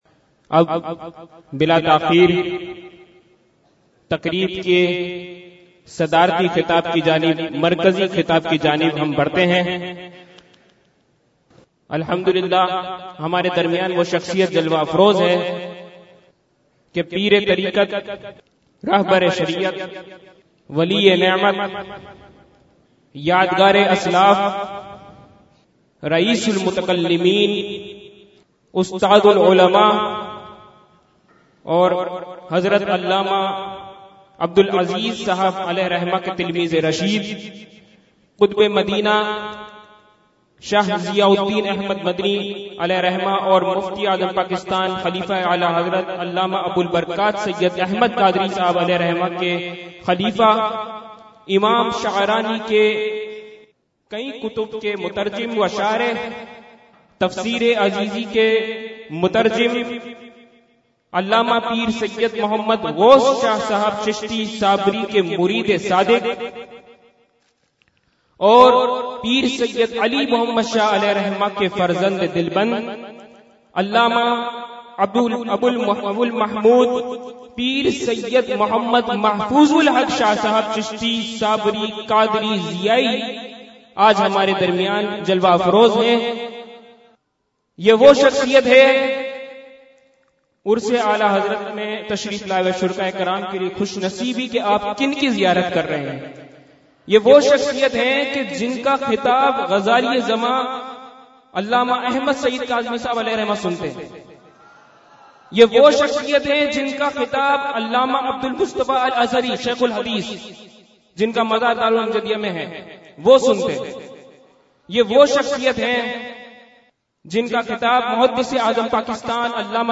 بیان بسلسلہ عرسِ اعلیٰ حضرت علیہ رحمہ ۱۴۳۸ھ